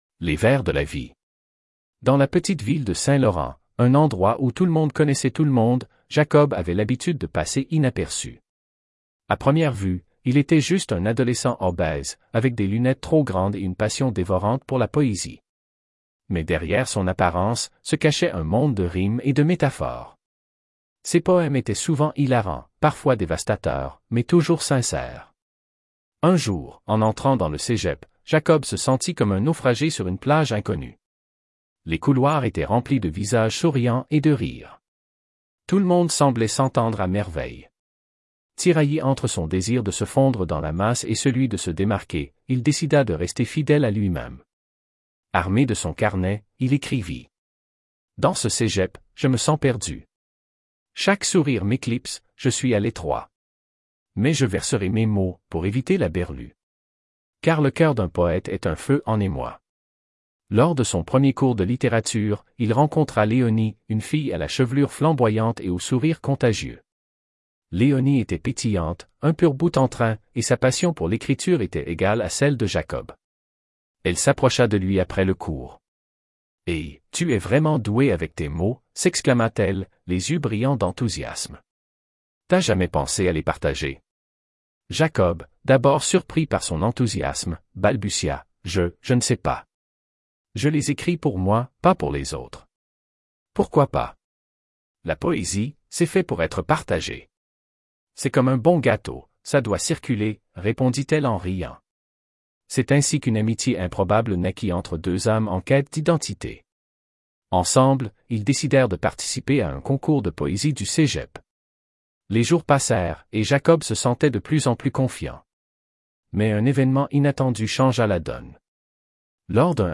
D'autres livres audio